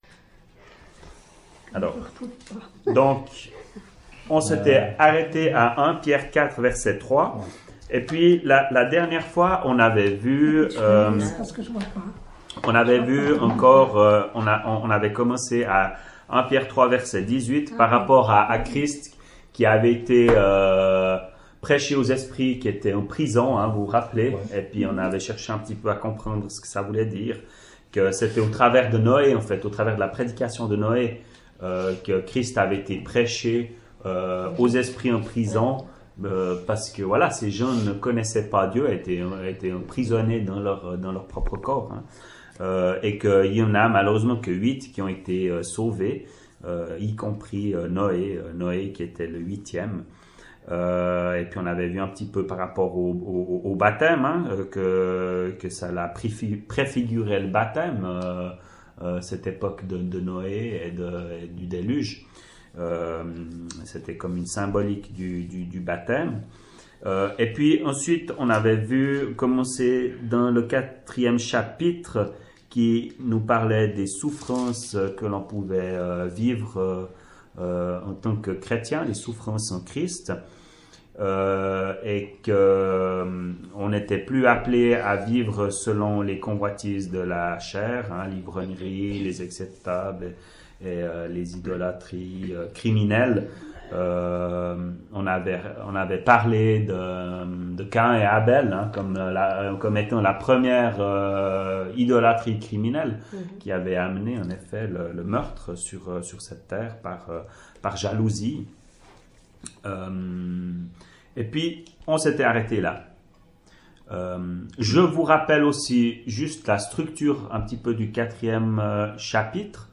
[Chapelle de l’Espoir] - Étude biblique : Première Épître de Pierre, 9ème partie
ÉTUDE BIBLIQUE : Evole, le 28.02.2018